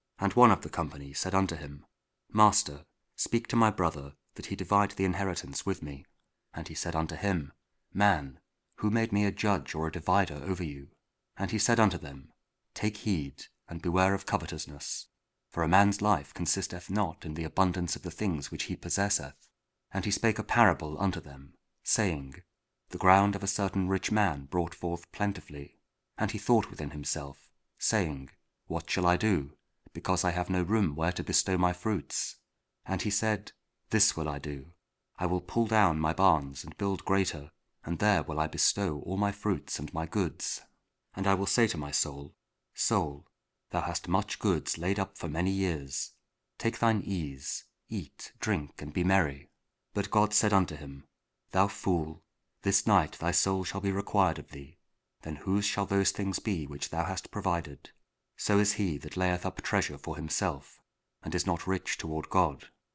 Luke 12: 13-21 – Sunday Week 18 (Year C) | Week 29 Ordinary Time, Monday (King James Audio Bible Spoken Word)